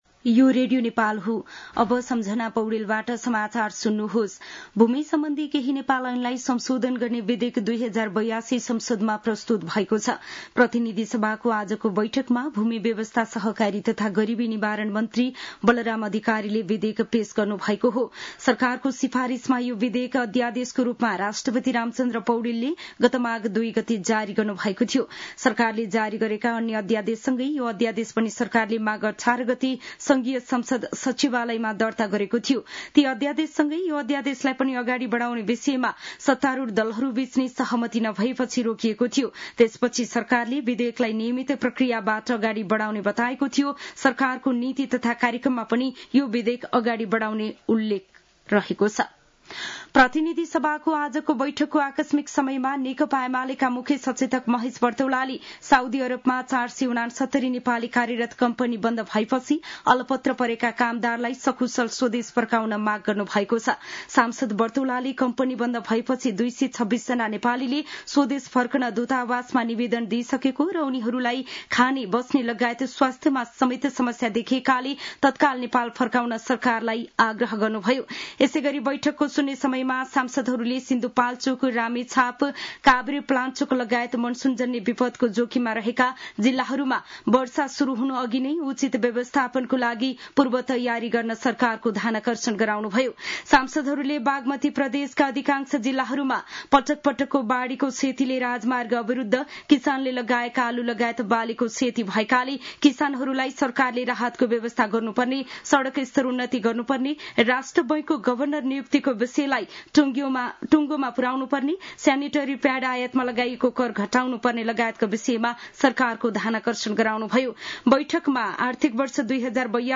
दिउँसो १ बजेको नेपाली समाचार : ३१ वैशाख , २०८२
1-pm-news.mp3